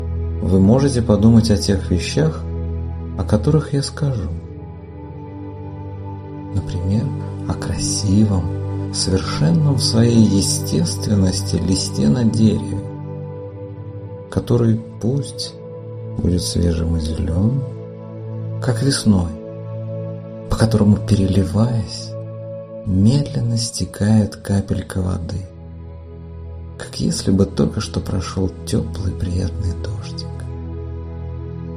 Аудиокнига Счастье вдвоём. Как создать гармоничные отношения | Библиотека аудиокниг